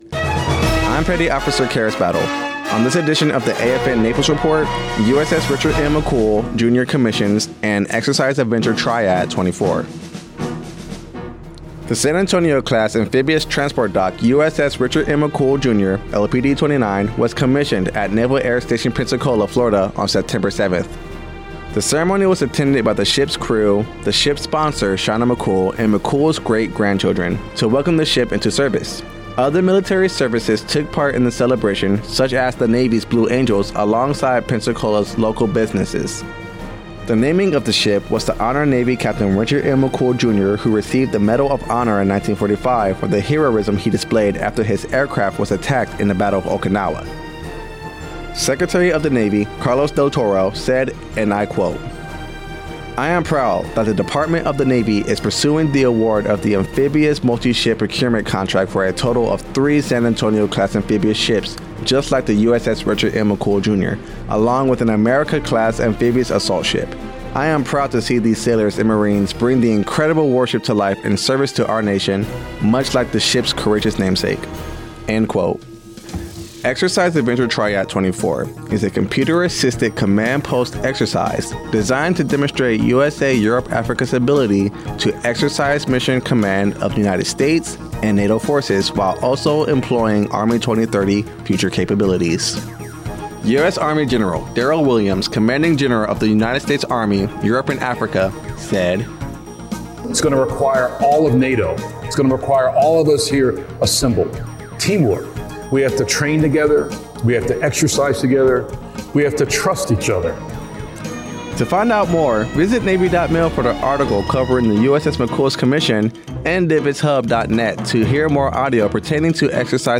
240910 AFN Naples Radio News